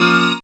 37jv01syn-a#.wav